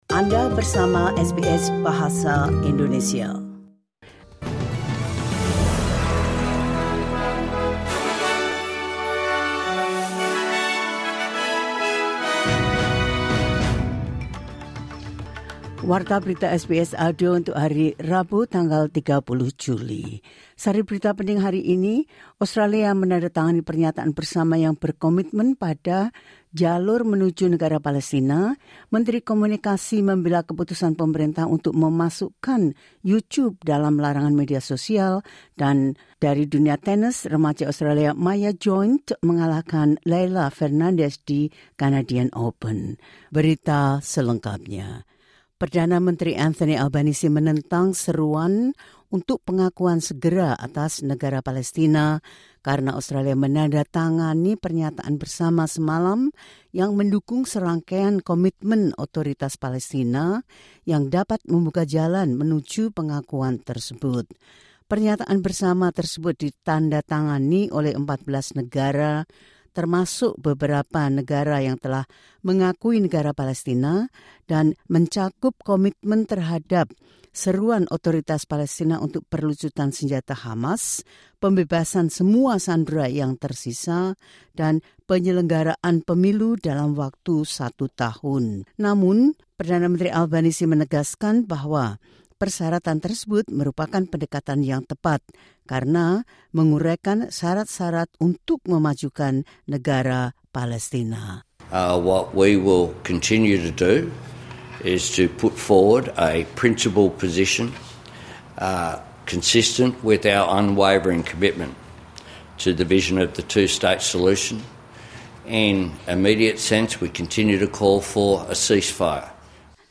Berita terkini SBS Audio Program Bahasa Indonesia – 30 Jul 2025
The latest news SBS Audio Indonesian Program – 30 Jul 2025.